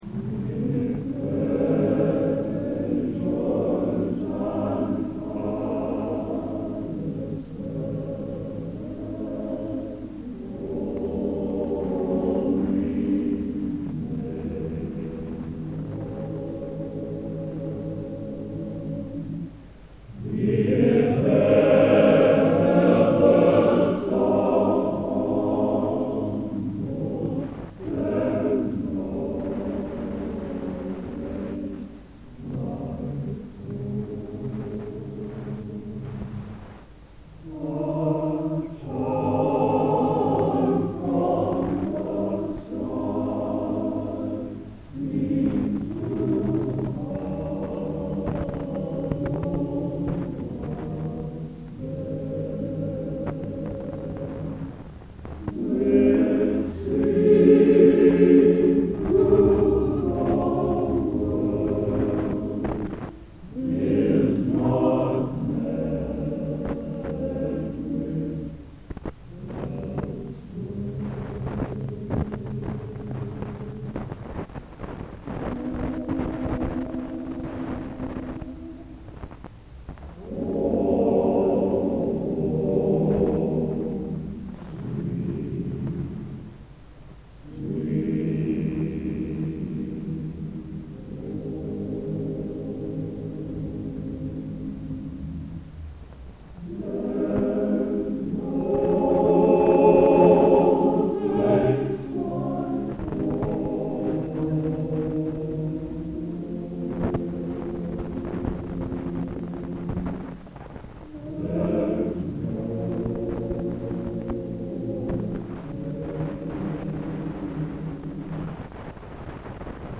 Collection: Plymouth, England
Location: Plymouth, England